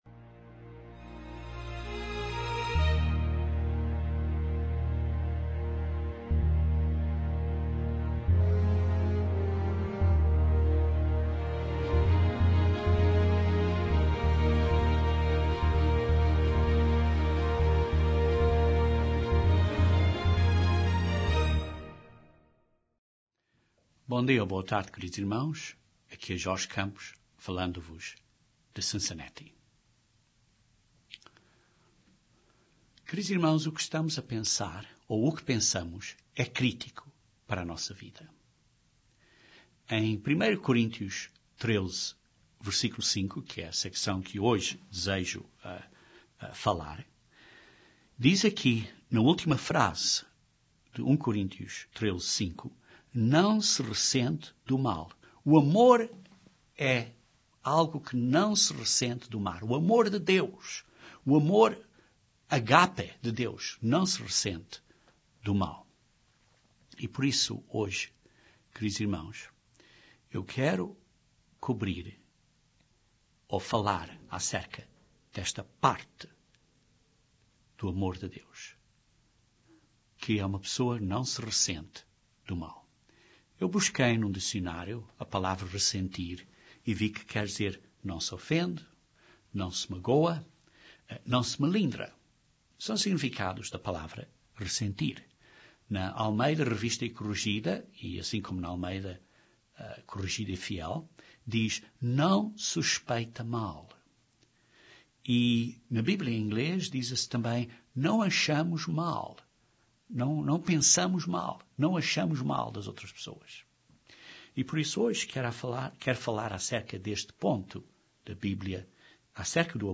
Este sermão nesta série de sermões acerca do amor (agape) de Deus, explica o significado importante do que é 'não suspeitar o mal'.